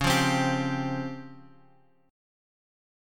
Listen to DbM#11 strummed